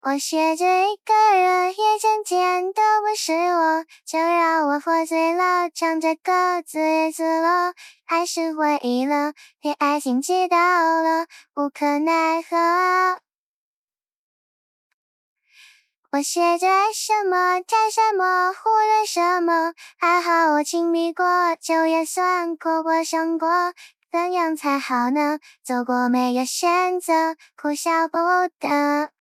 本次更新对底噪修复以及优化了唱歌的部分细节，解决了翻唱时候大量的底噪问题。
推理后的预览将会使用干音预览，其他推理是经过后期的。
唱歌支持